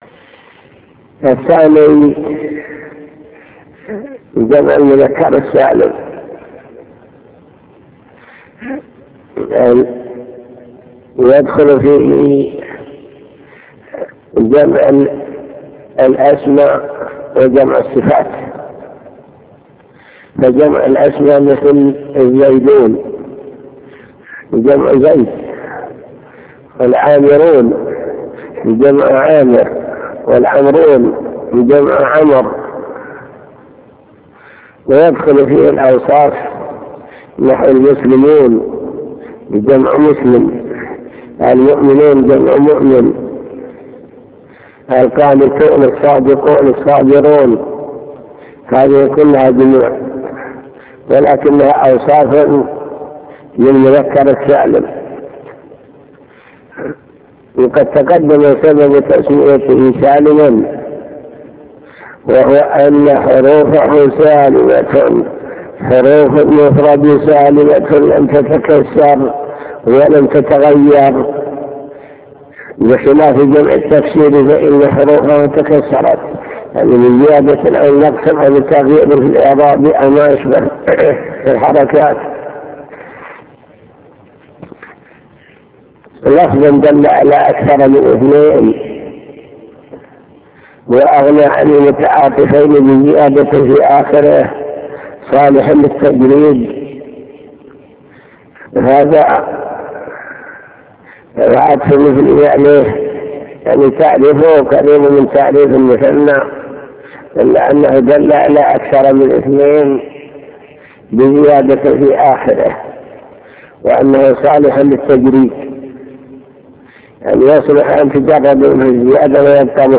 المكتبة الصوتية  تسجيلات - كتب  شرح كتاب الآجرومية باب الإعراب علامات الإعراب حروف الإعراب.. وما يعرب بها